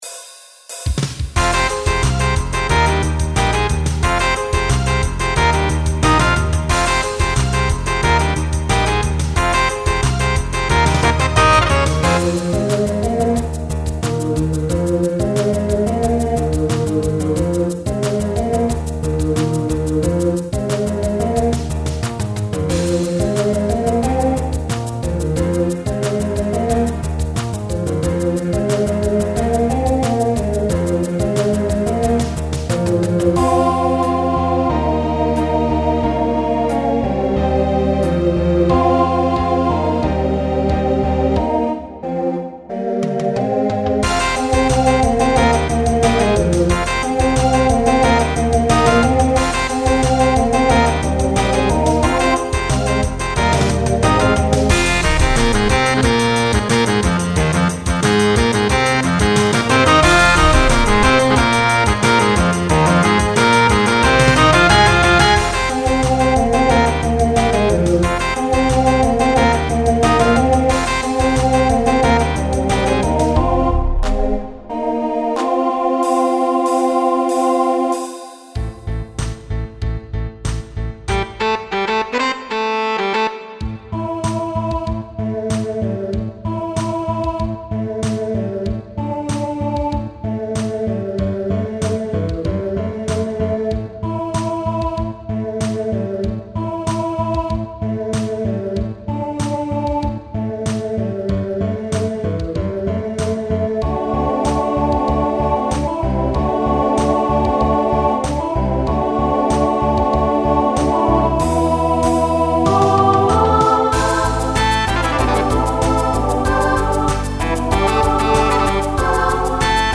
with a little bit of SATB at the end.
Voicing Mixed